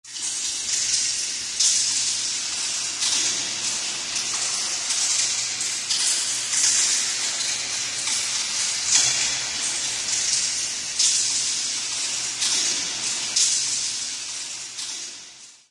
一个地下洞穴滴水声
描述：水龙头滴水声添加了一些混响和轻微的延迟。 一个地下洞穴的滴水声效果。
标签： 回声 延迟 地下 洞穴 滴水 液滴 水滴 掉落 混响
声道立体声